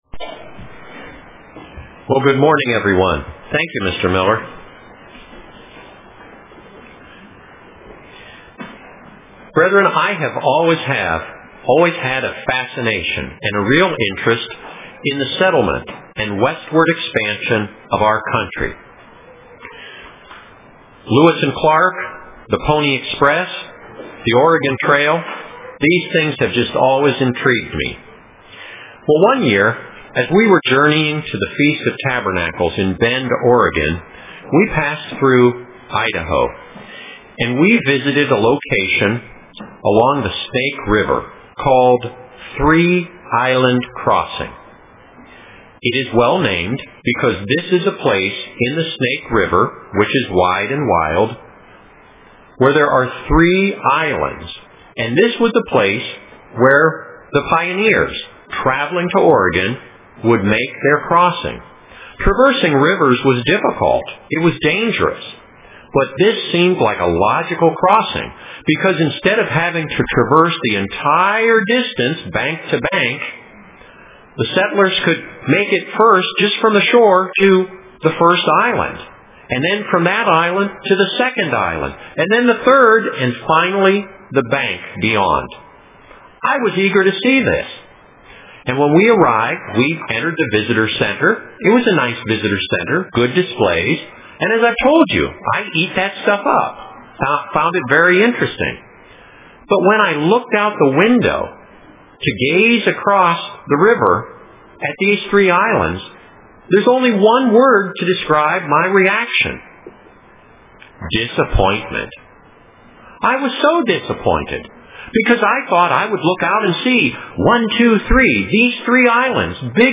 Print God see's more than I do UCG Sermon Studying the bible?